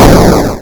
se_boom.wav